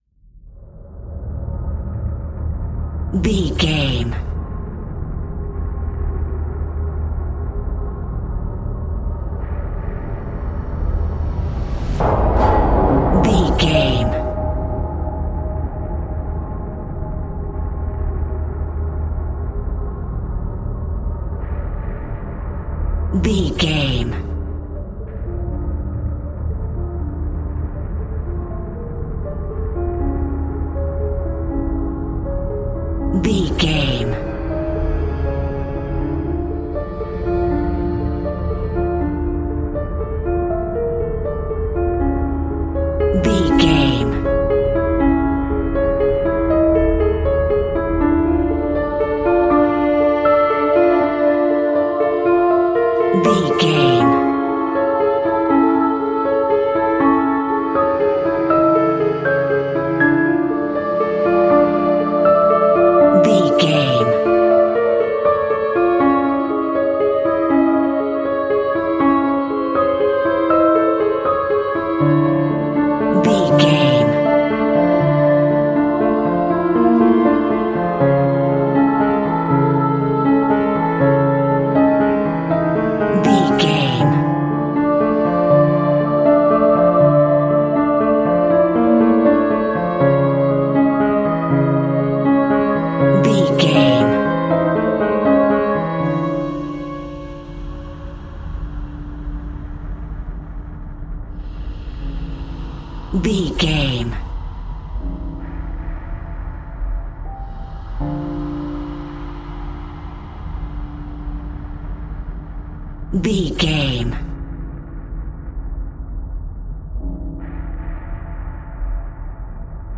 Thriller
Aeolian/Minor
D
ominous
dark
eerie
piano
strings
synth
ambience
pads